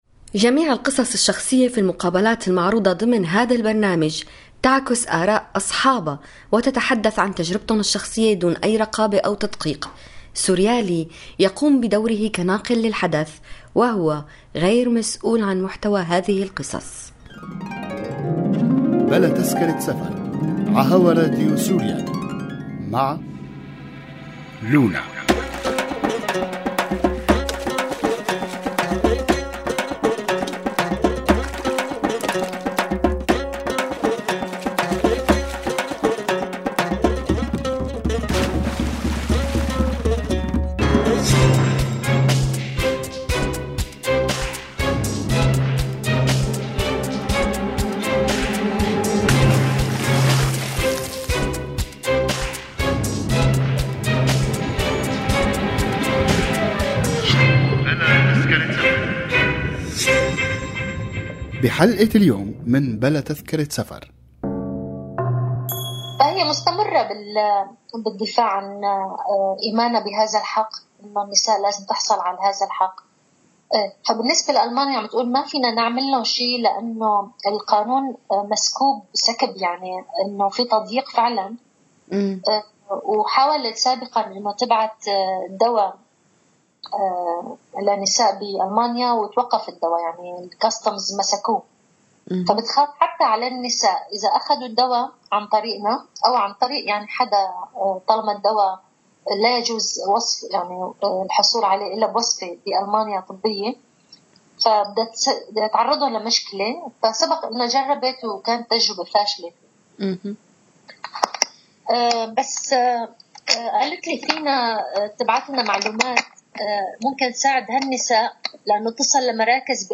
لقاء مع راديو سوريالي